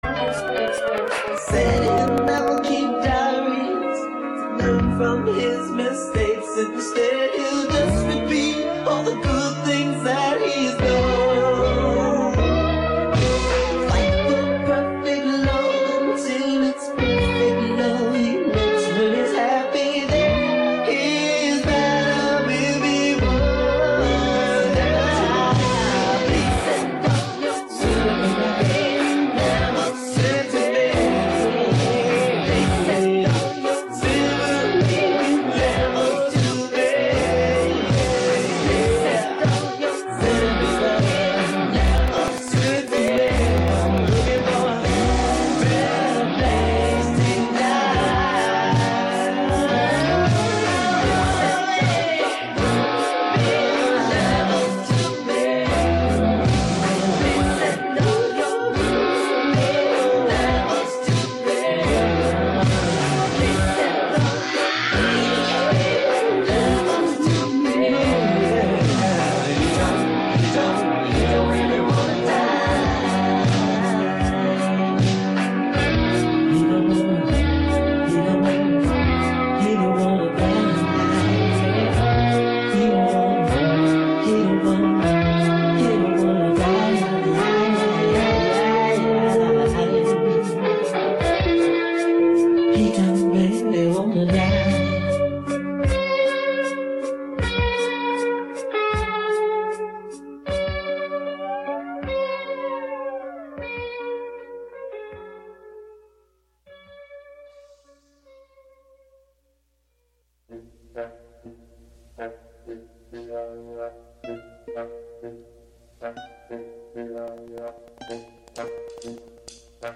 Interview recorded in the WGXC Hudson Studio on Thu., May 2.